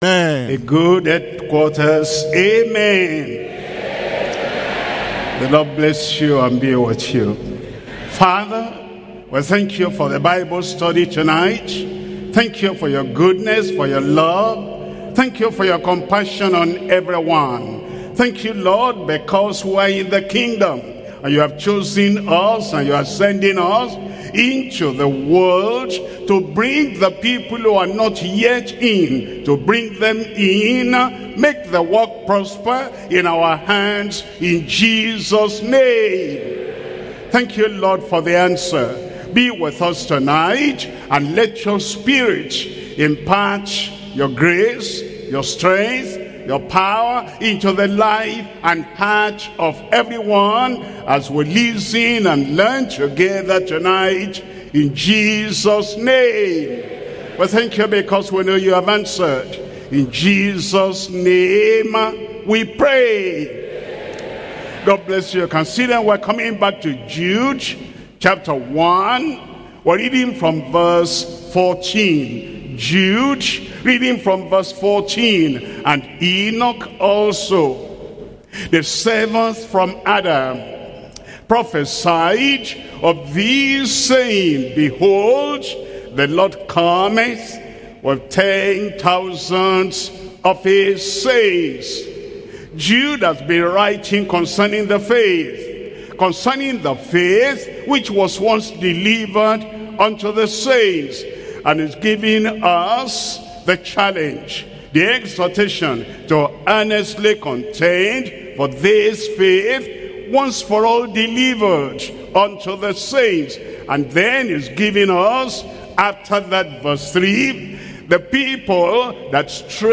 Pastor W.F. Kumuyi
Bible Study